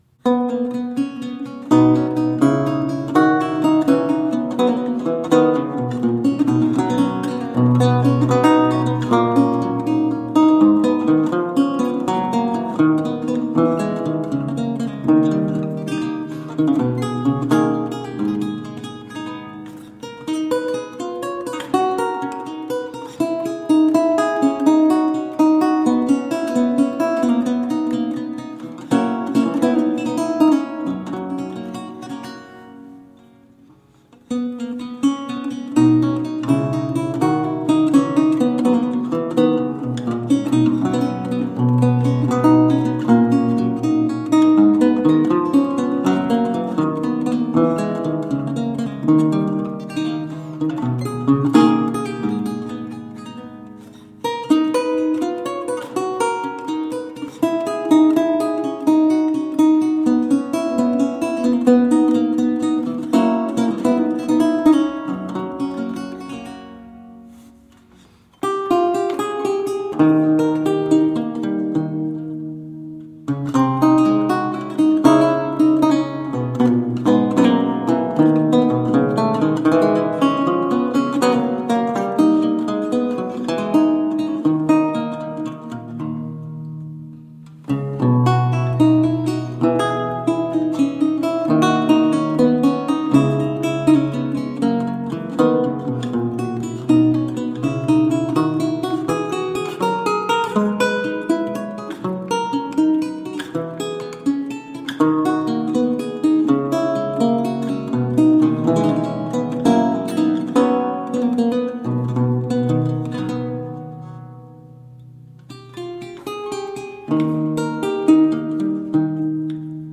Vihuela, renaissance and baroque lute.